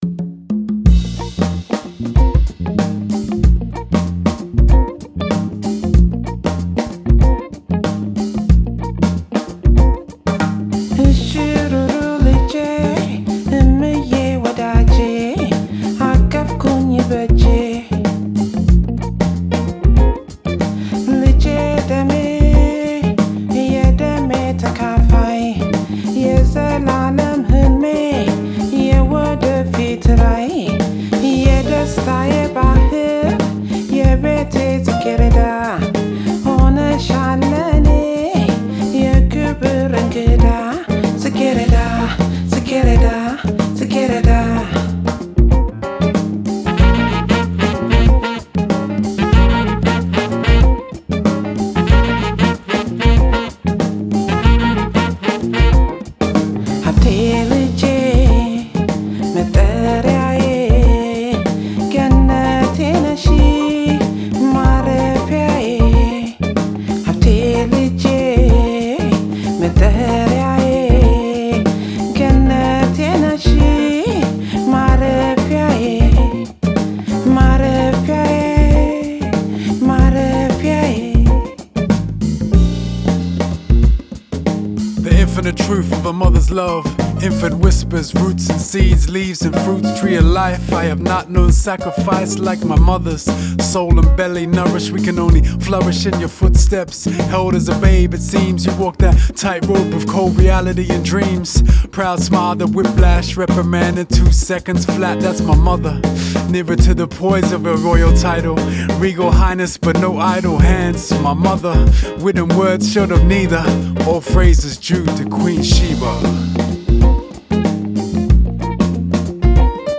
have a beguiling Afrobeat bounce